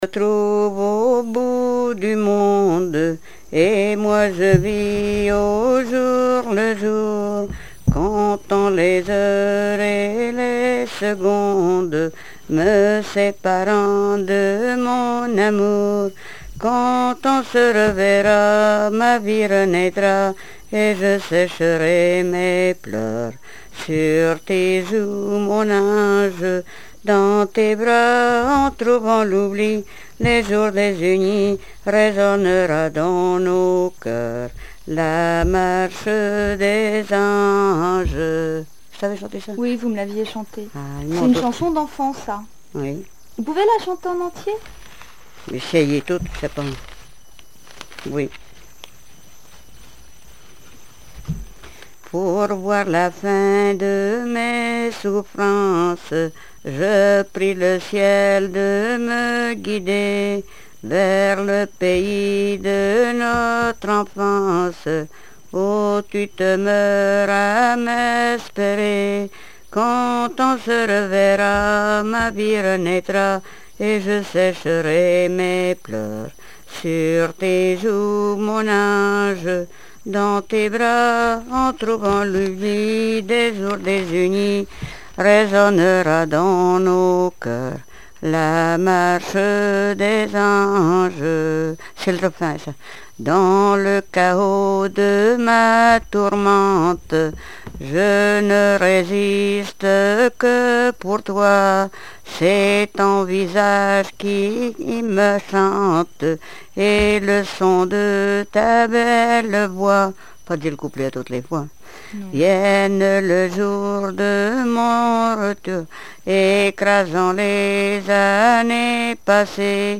Noël
Répertoire de chansons traditionnelles et populaires
Pièce musicale inédite